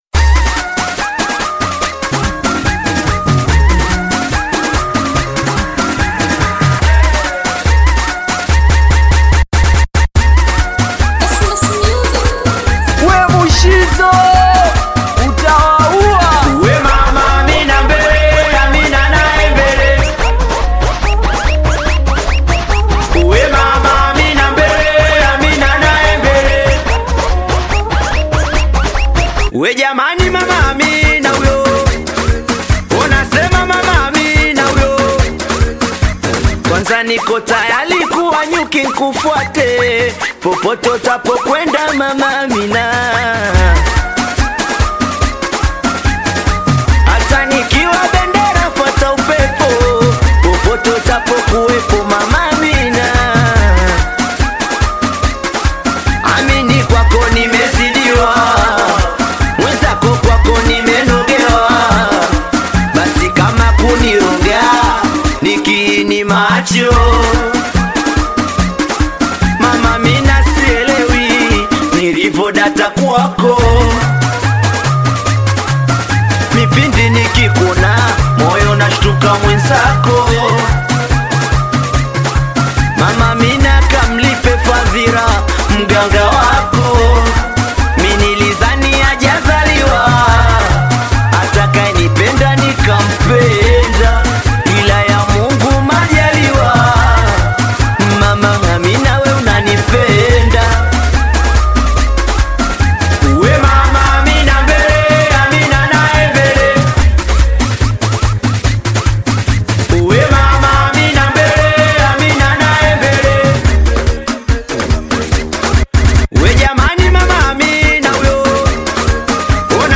Tanzanian Bongo Flava
Singeli song